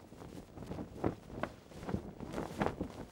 cloth_sail8.R.wav